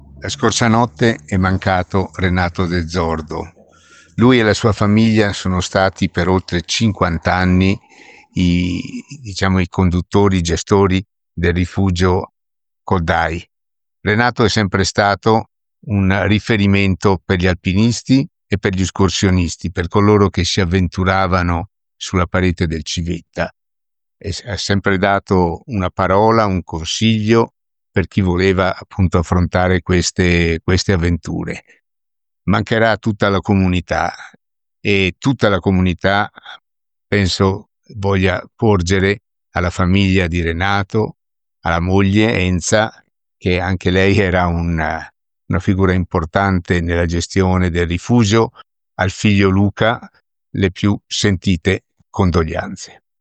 IL MESSAGGIO DEL SINDACO DI ALLEGHE DANILO DE TONI